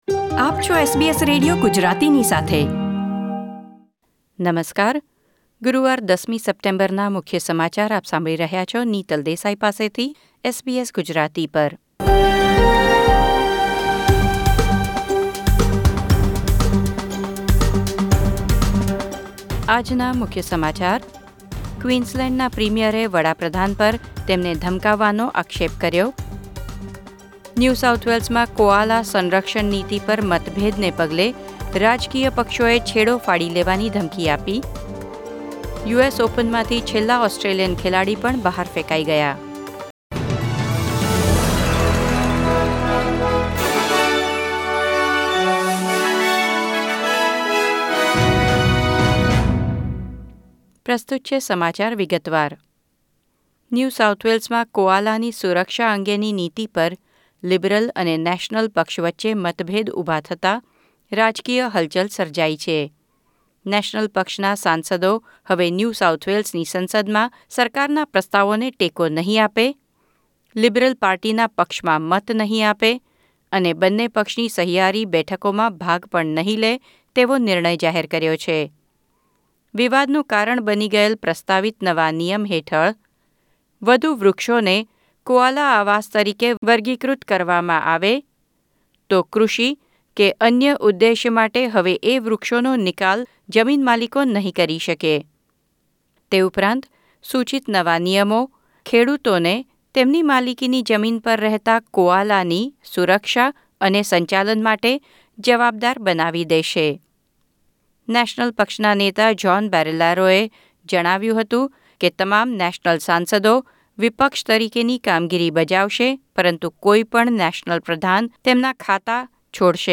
SBS Gujarati News Bulletin 10 September 2020